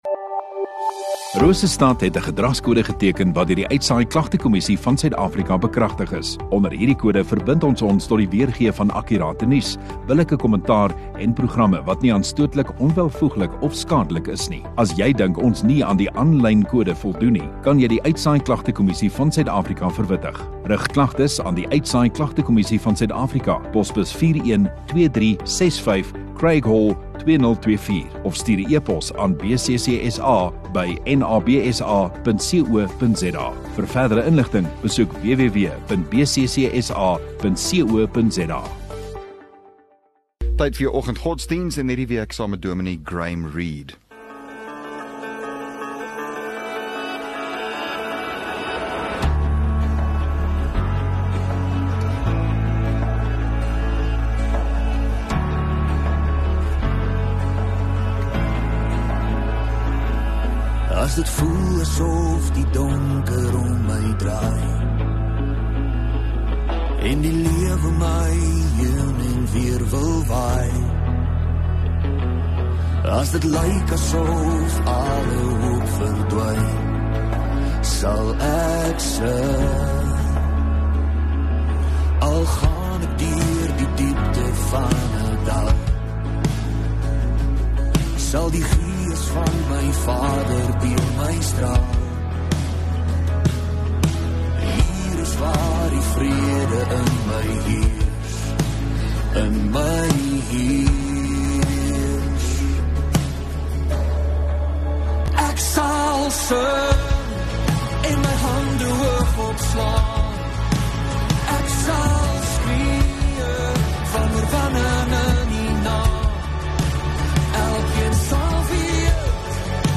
15 Apr Dinsdag Oggenddiens